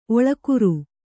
♪ oḷakuru